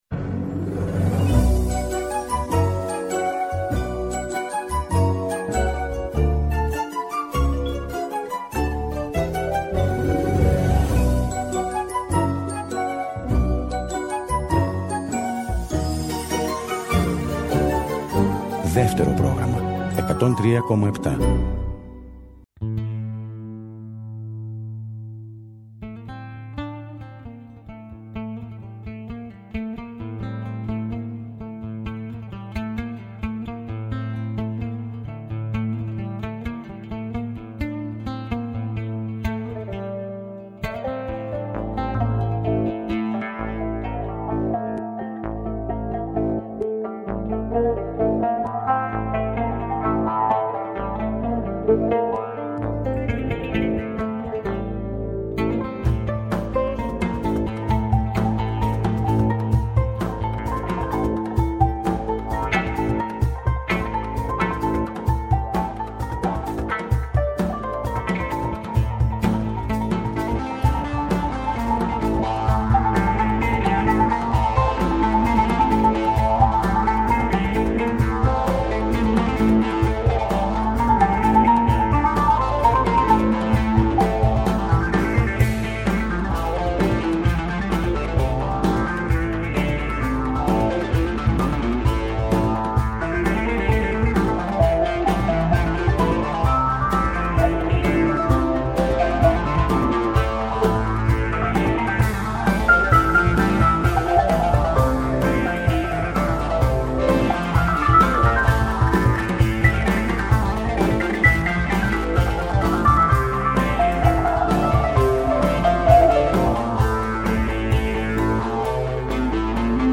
ούτι- φωνή
κρουστά
κανονάκι
Live στο Studio